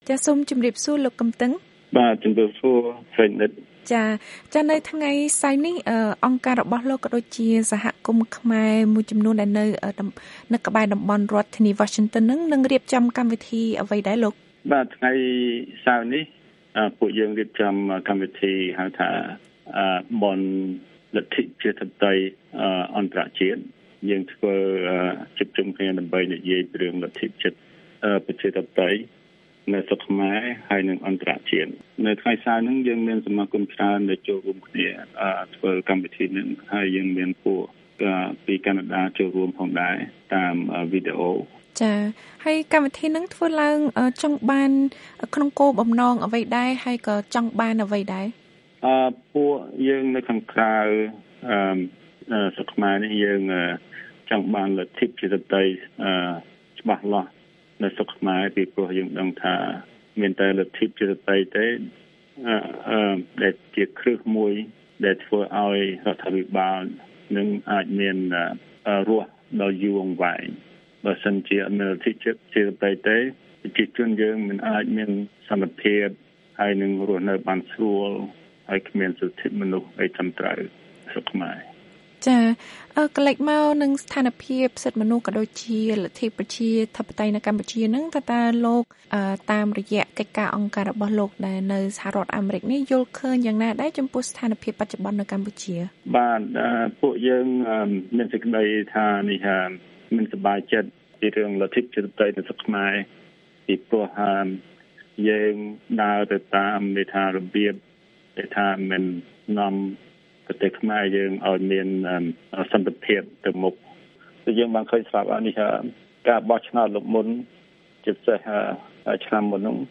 បទសម្ភាសន៍ VOA៖ សហគមន៍ខ្មែរអាមេរិកាំងនឹងជួបជុំគ្នាស្វែងរកដំណោះស្រាយជួយស្តារលទ្ធិប្រជាធិបតេយ្យនៅកម្ពុជា